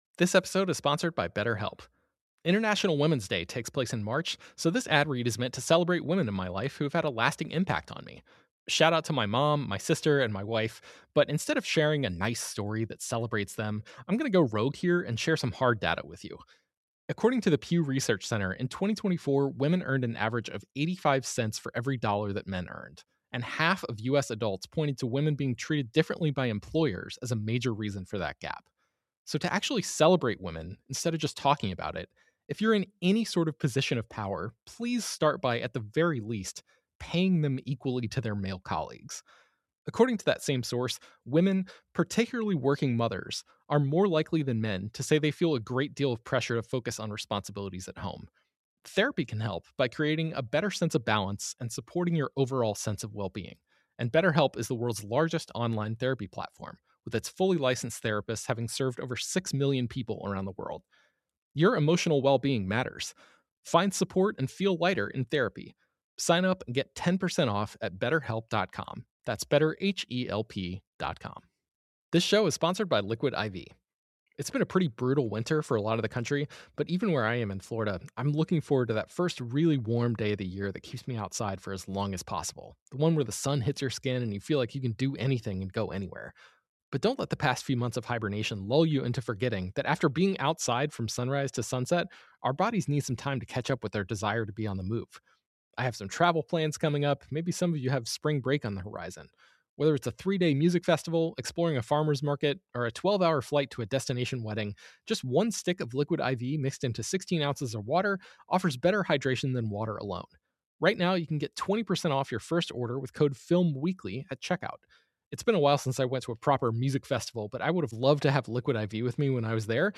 In Our Feature Presentation, we talk with Joker director Todd Phillips and star Joaquin Phoenix.